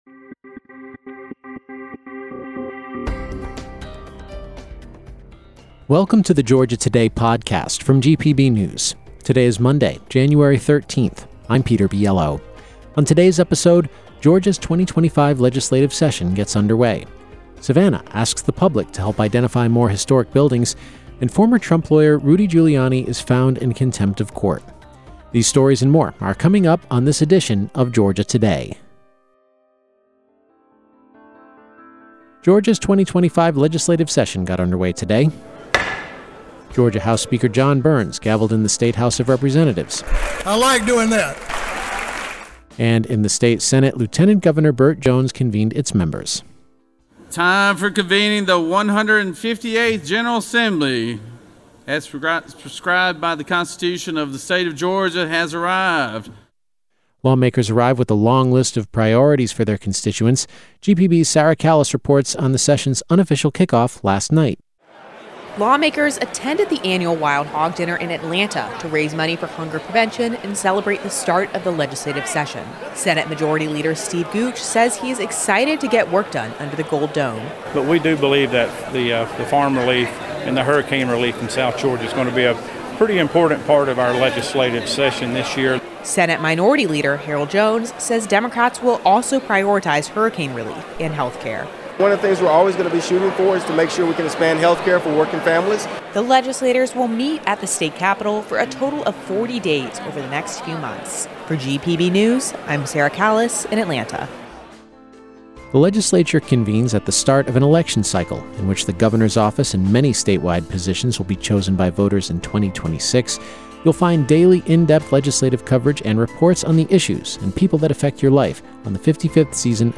Georgia Today is the daily podcast from GPB News bringing you compelling stories and in-depth reporting that you won’t hear anywhere else.